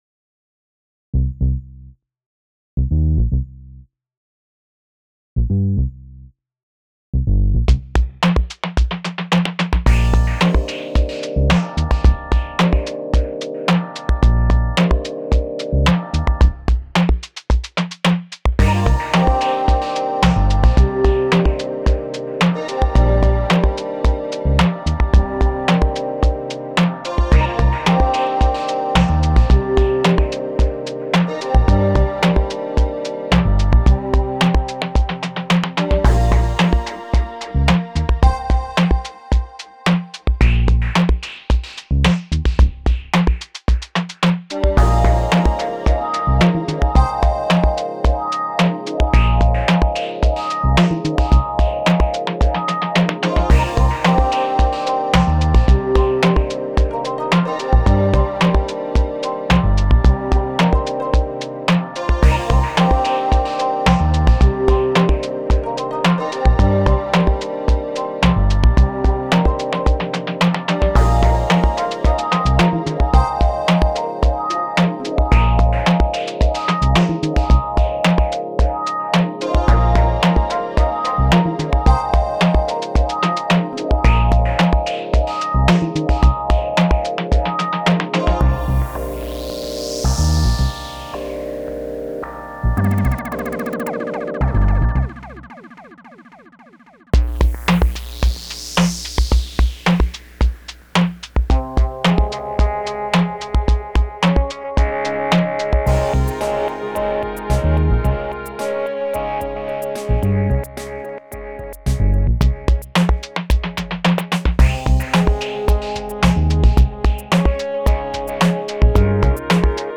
Chillout